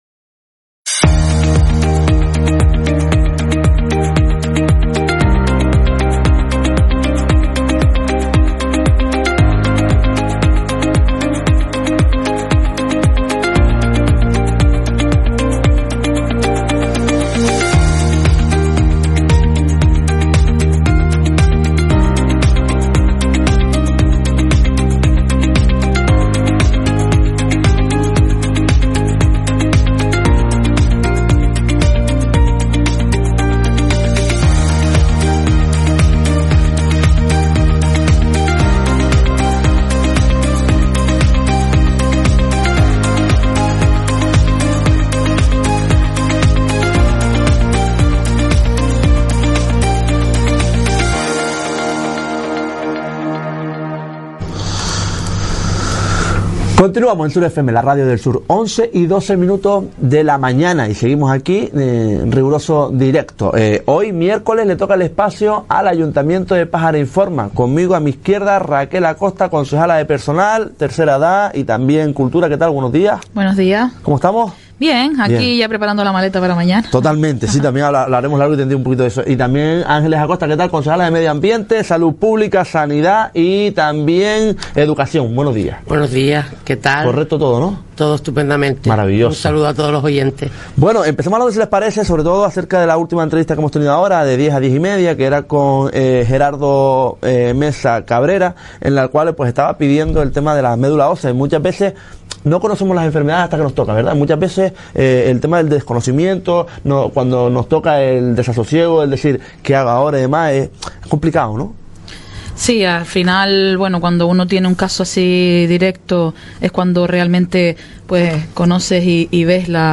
Todos los miércoles les toca el turno al grupo de gobierno de Pájara. Nos visitaba el pasado día 10 de abril la concejala Raquel Acosta y Ángeles Acosta.